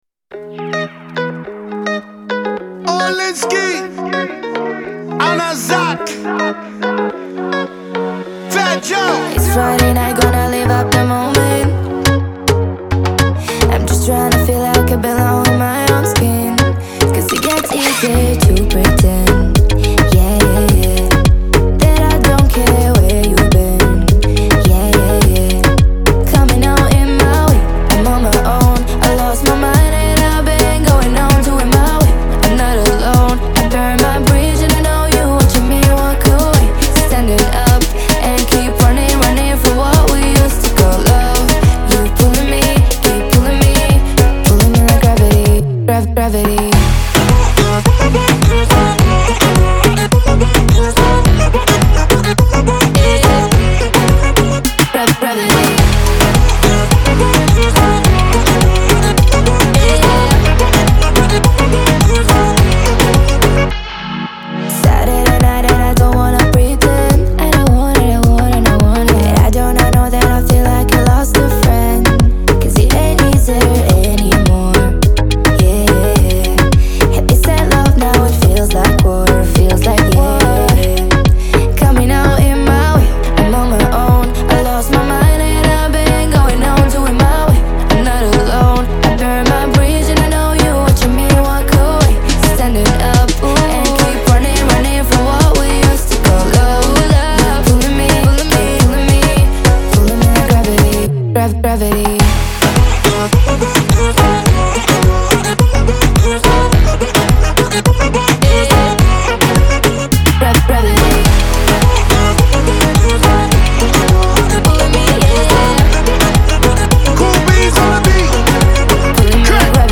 это яркий трек в жанре поп и хип-хоп